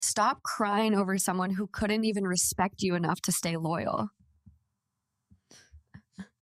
Advice (Angry clarity): Stop crying sound effects free download